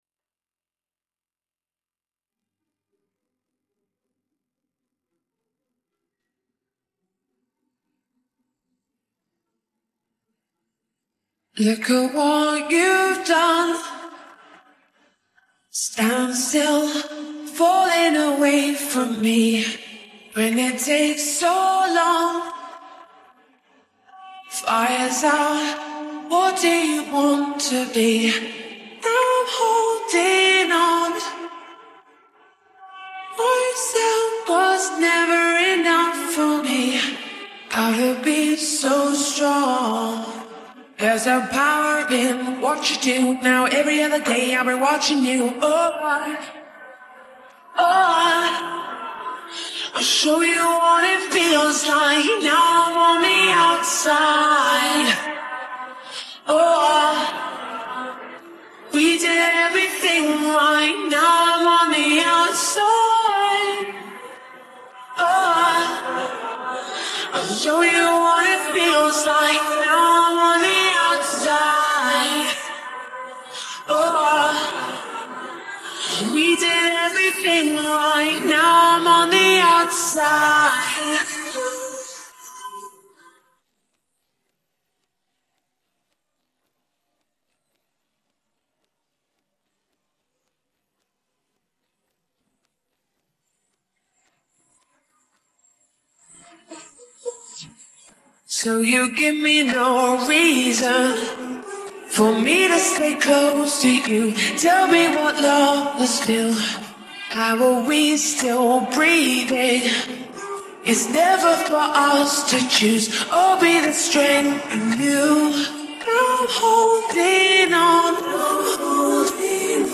Human voices and background music
Vocal Part